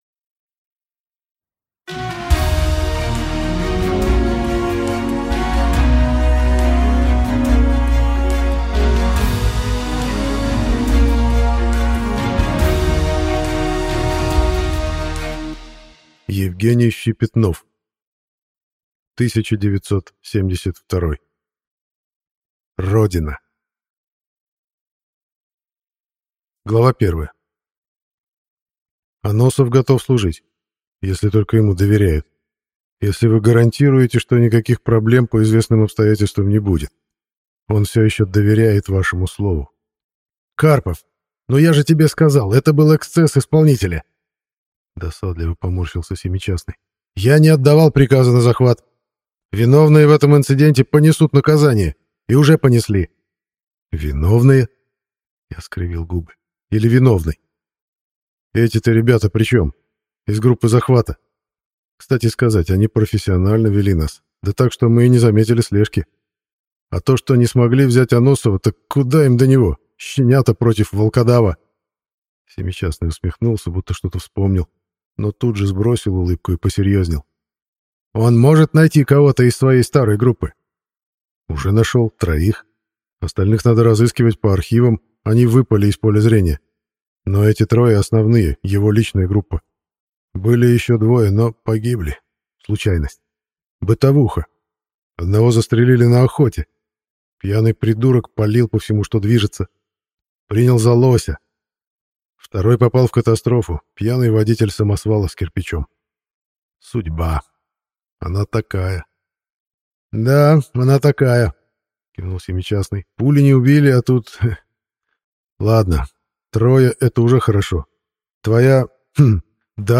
Аудиокнига 1972. Родина - купить, скачать и слушать онлайн | КнигоПоиск